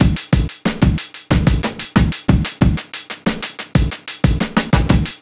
Back Alley Cat (Drums 02).wav